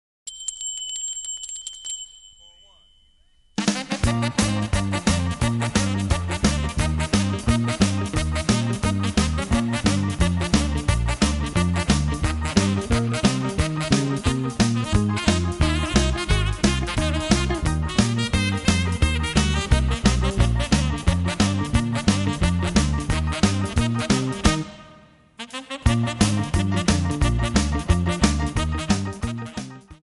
Backing track files: Oldies (1113)